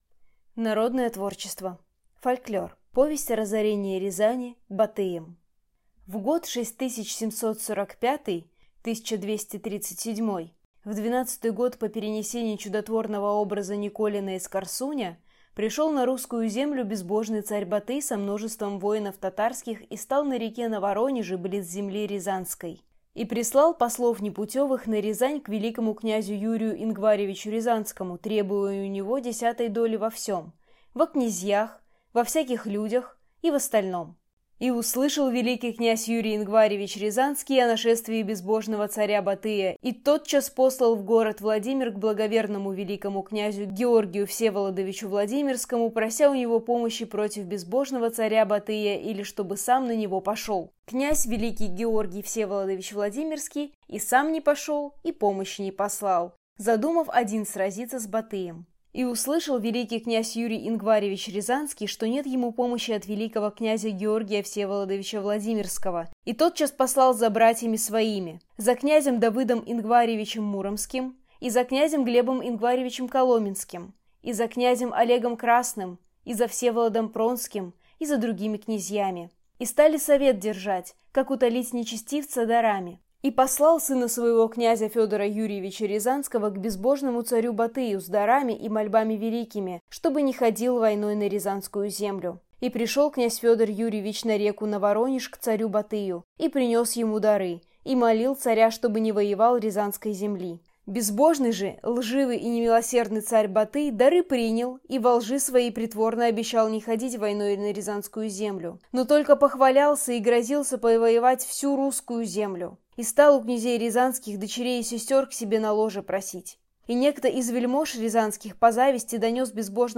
Аудиокнига Повесть о разорении Рязани Батыем | Библиотека аудиокниг